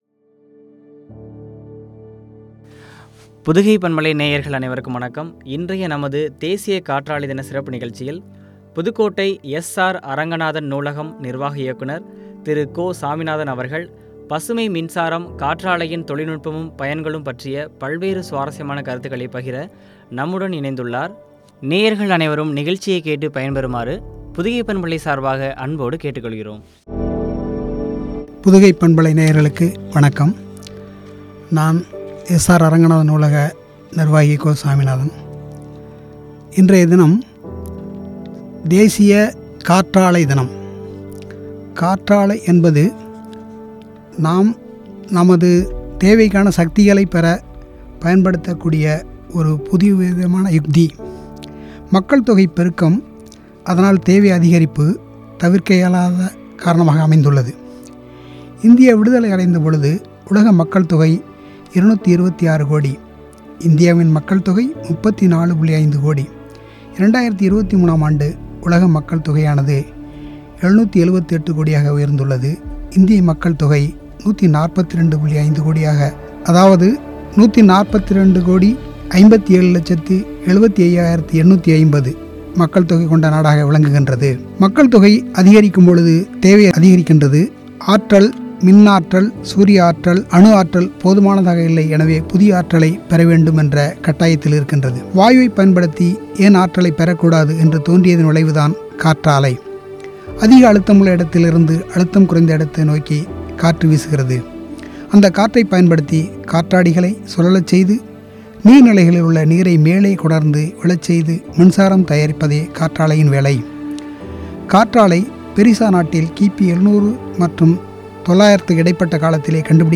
பயன்களும் பற்றிய உரையாடல்.